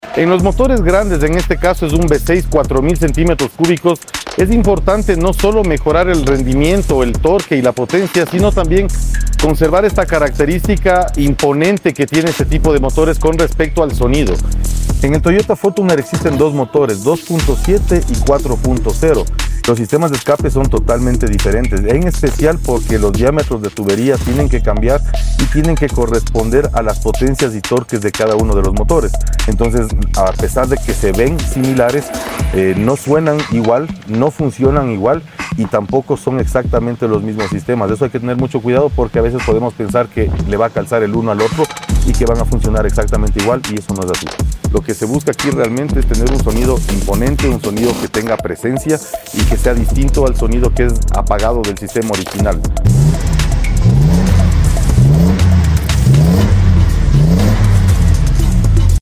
¡El sonido de la Toyota sound effects free download
¡El sonido de la Toyota Fortuner 4.0 ahora marca un verdadero sentimiento de potencia! En AutoX, hemos mejorado su sistema de escape para que su rugido sea imponente y emocionante.